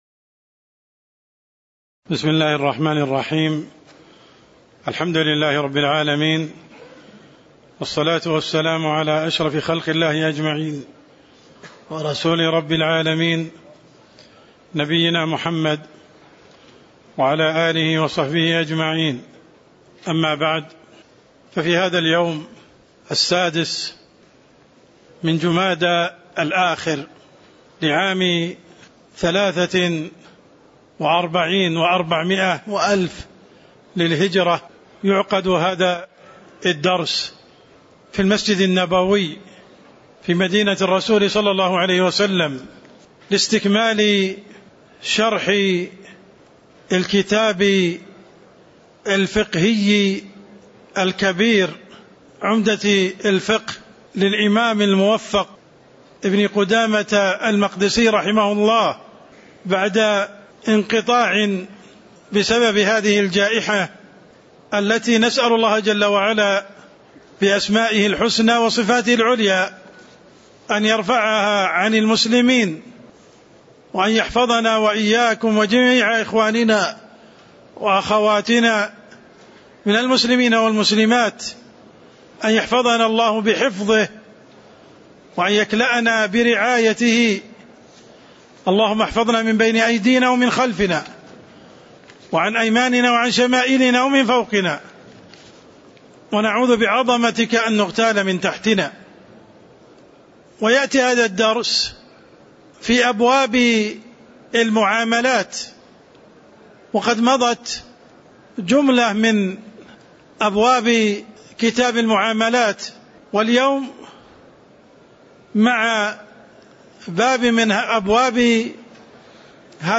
تاريخ النشر ٦ جمادى الآخرة ١٤٤٣ هـ المكان: المسجد النبوي الشيخ: عبدالرحمن السند عبدالرحمن السند باب الصلح (09)